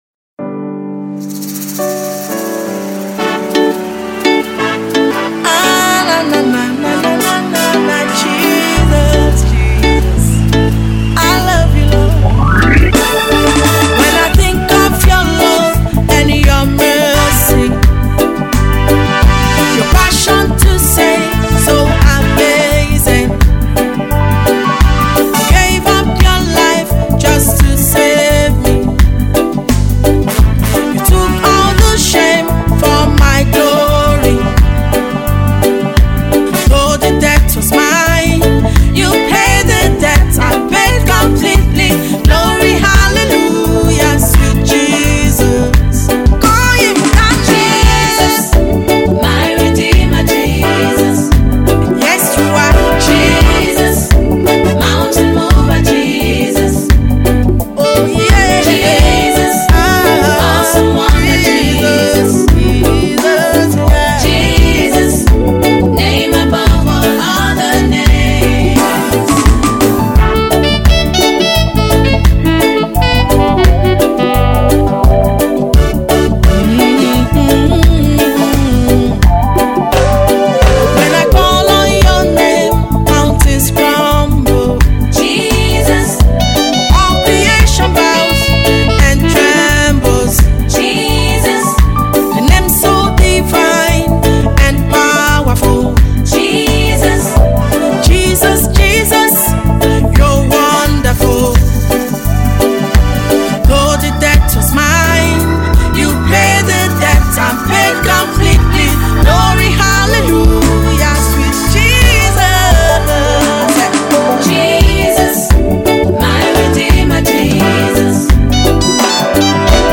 energetic melody